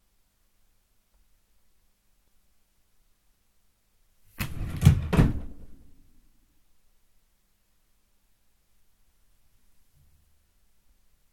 Fast/Close Drawer 4
Duration - 11 s Environment - Bedroom, absorption of curtains, carpet and bed. Description - Close, pulled fast, grabs, slams, wooden drawer, bangs close